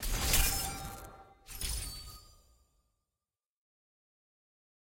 sfx-exalted-chase-reward-celebration-anim.ogg